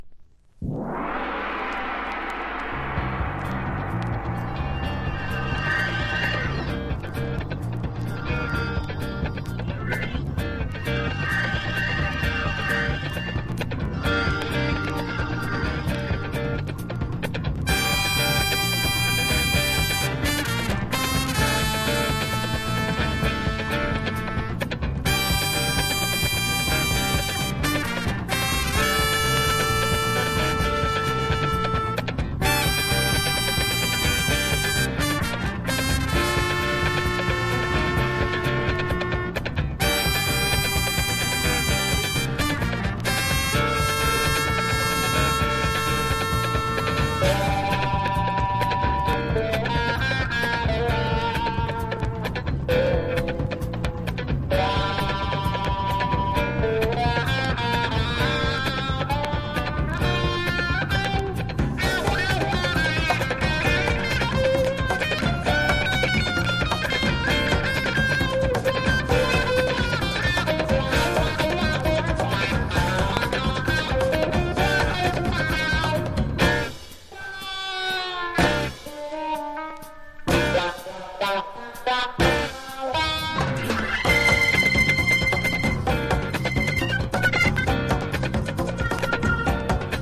FUNK / DEEP FUNK